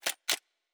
pgs/Assets/Audio/Sci-Fi Sounds/Weapons/Weapon 02 Reload 3.wav at master
Weapon 02 Reload 3.wav